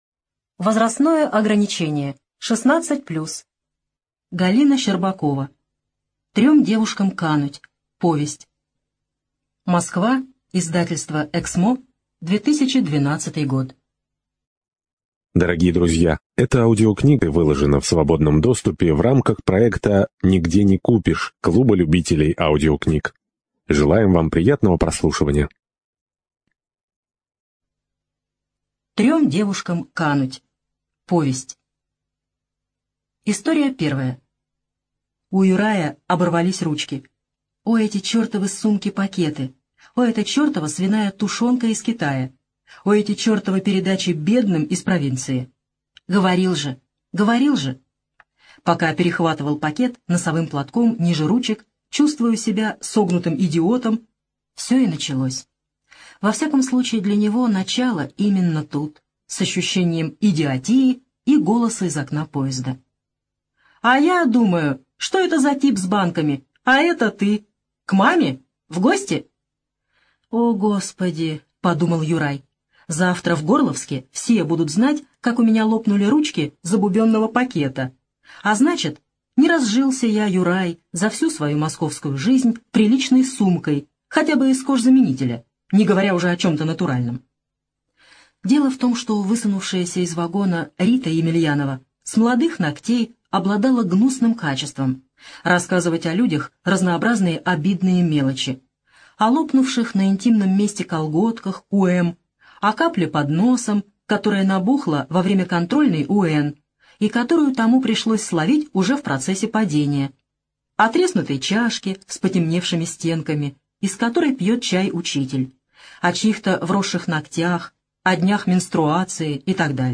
ЖанрДетективы и триллеры
Студия звукозаписиКемеровская областная специальная библиотека для незрячих и слабовидящих